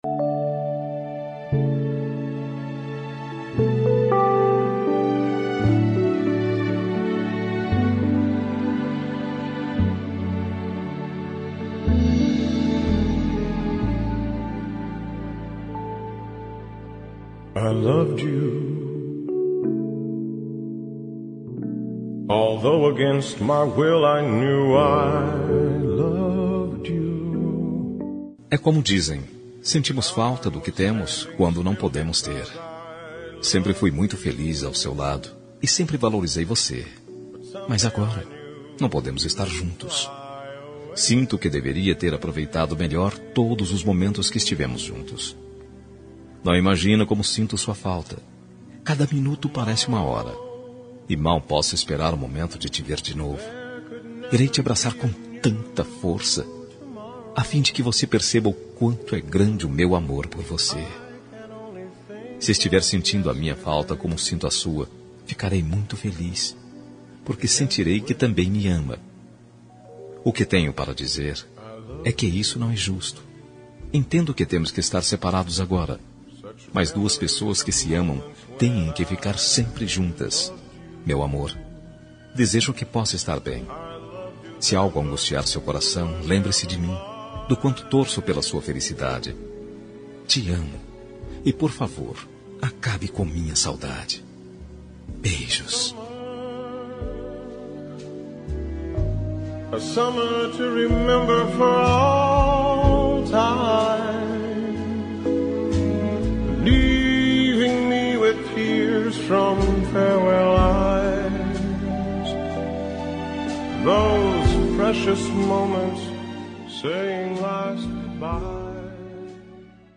Telemensagem de Saudades – Voz Masculina – Cód: 456
456-saudades-masc-1.m4a